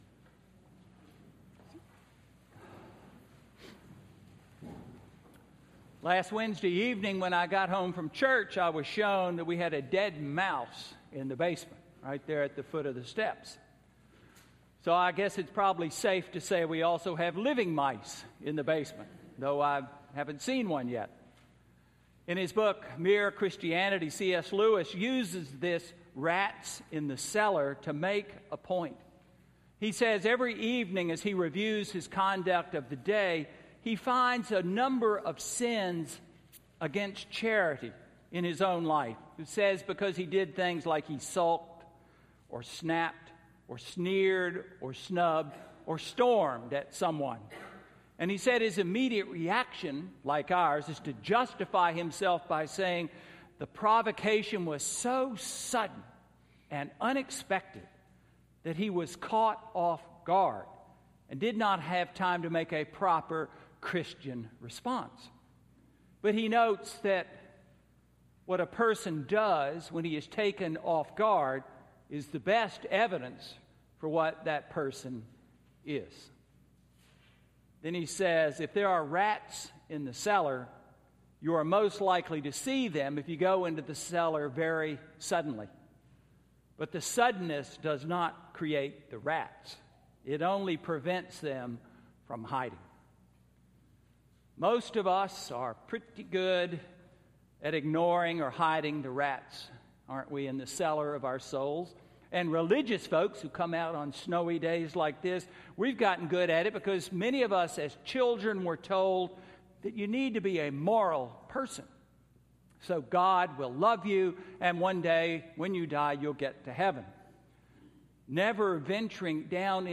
Sermon–February 16, 2014